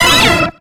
Cri d'Hypocéan dans Pokémon X et Y.